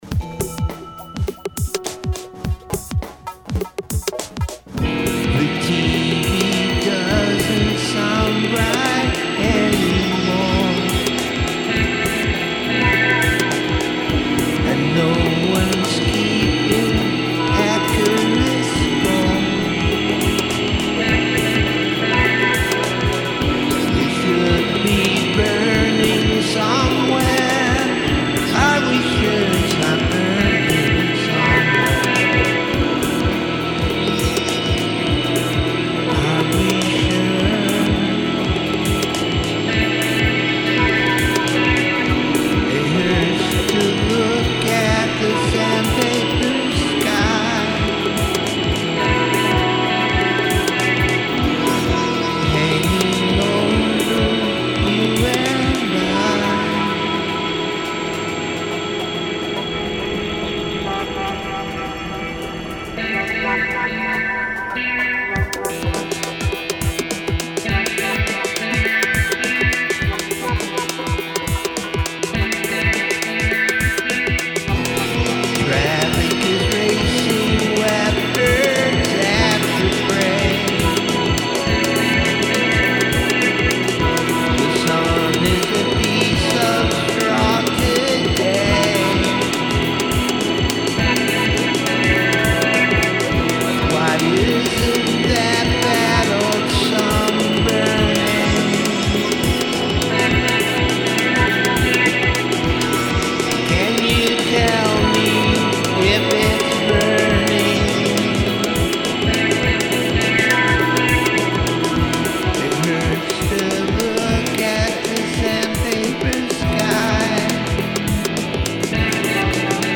rock and/or roll